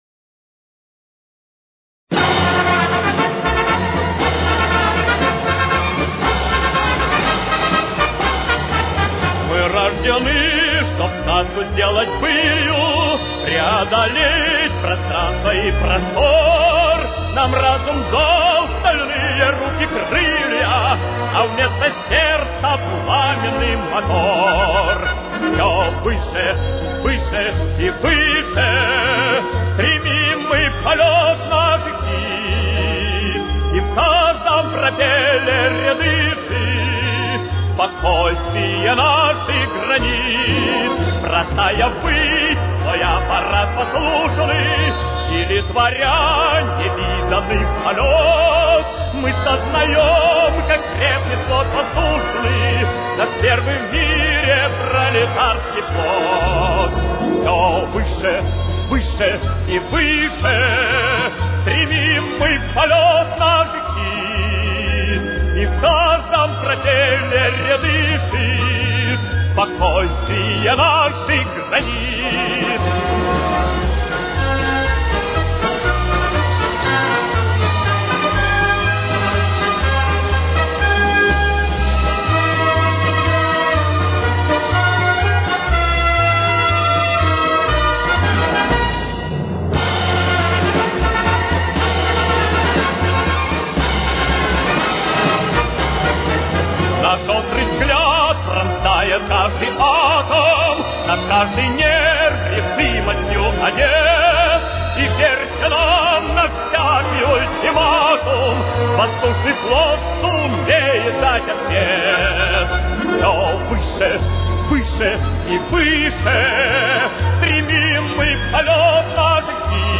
с очень низким качеством (16 – 32 кБит/с)
Си-бемоль мажор. Темп: 119.